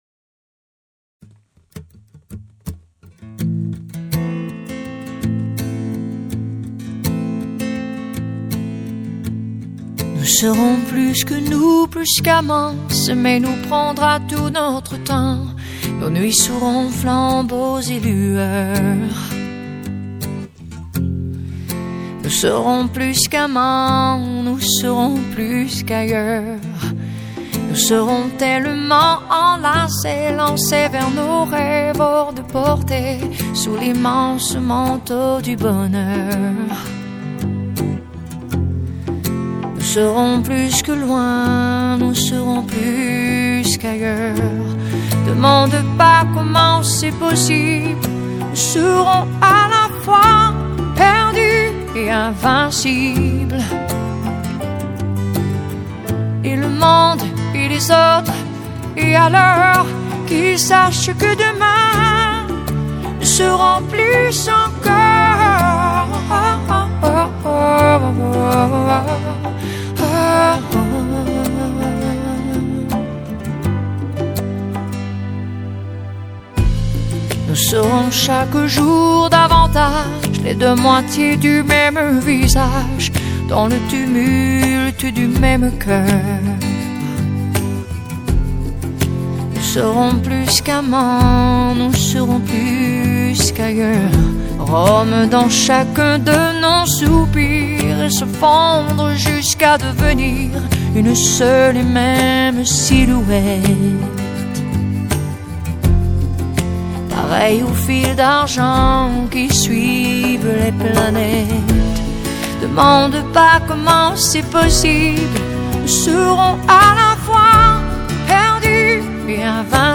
Pop, Ballad